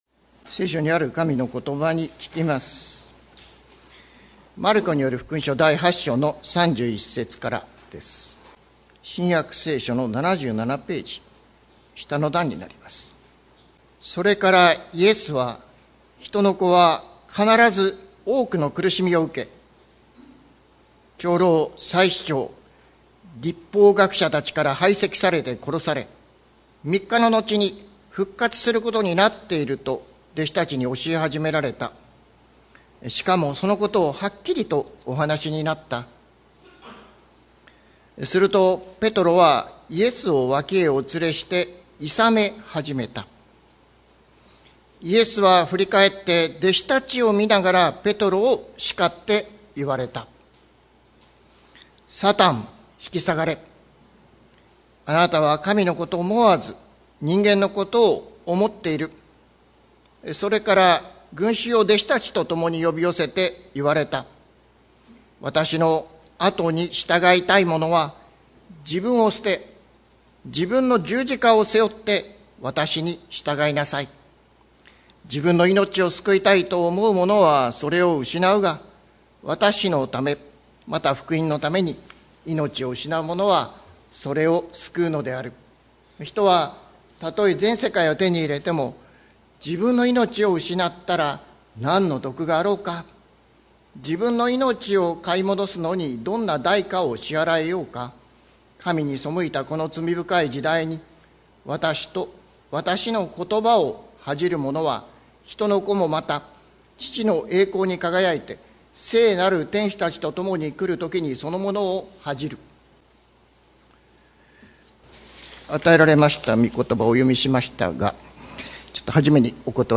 2026年03月15日朝の礼拝「十字架のイエスに従う」関キリスト教会
説教アーカイブ。